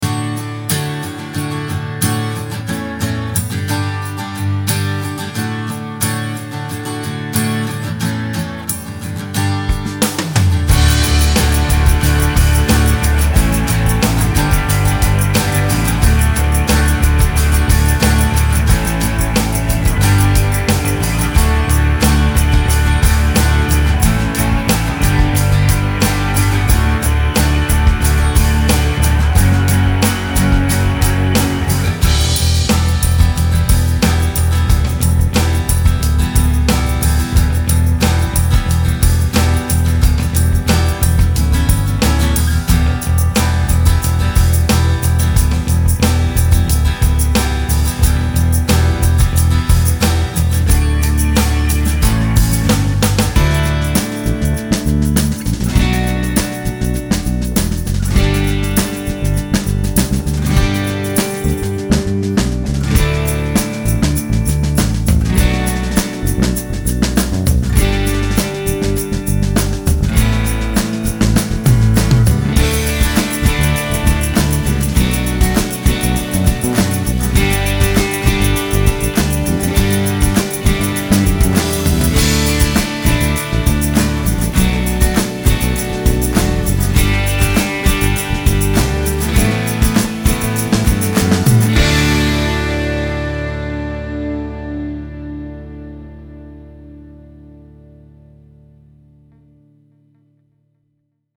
铃声与叮当声
这是一套多功能的现场录制金属打击乐音源合集，可为您的歌曲增添闪烁的质感与明亮的节奏点缀。
包含乐器： 铃鼓1、铃鼓2、铃鼓3、阿戈戈铃、铃铛与木块以及三角铁。
Tambourine_1_Demo.mp3